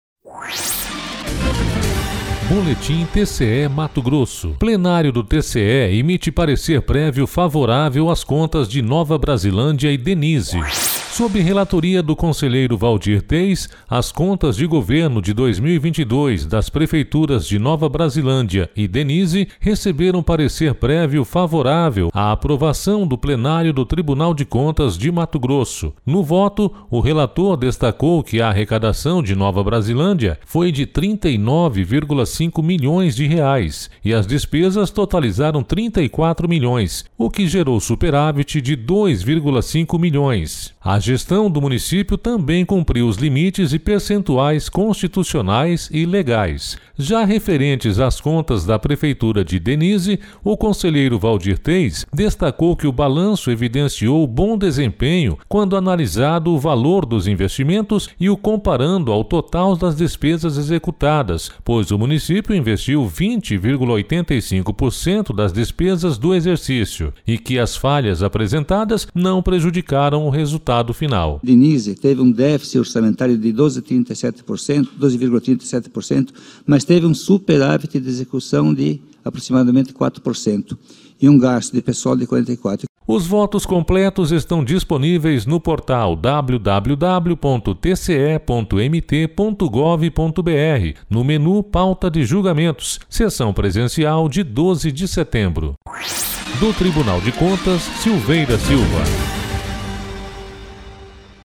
Sonora: Waldir Júlio Teis – conselheiro do TCE-MT